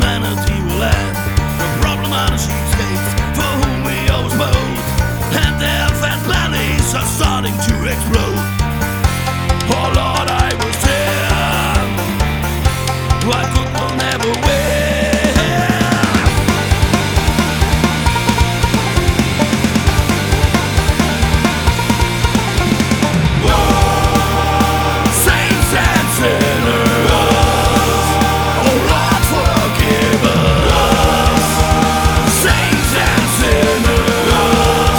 Жанр: Альтернатива
Alternative